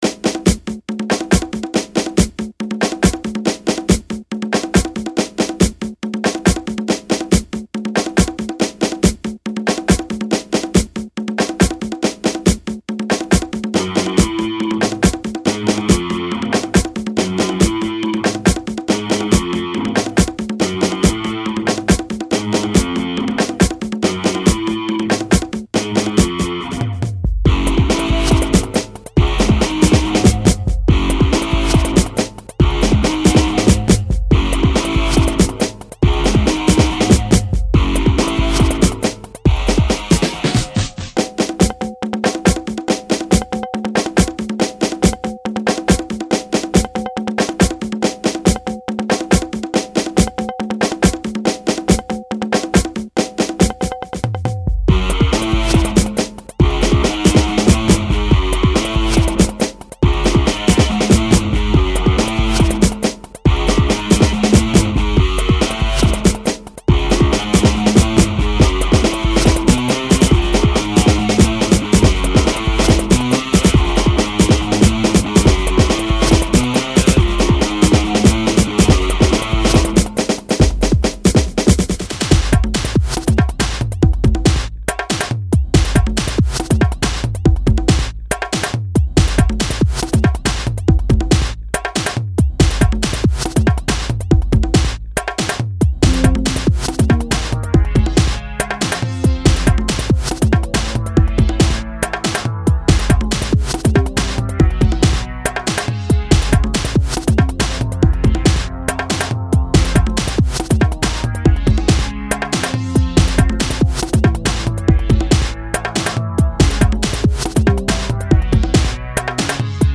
• Жанр: Электронная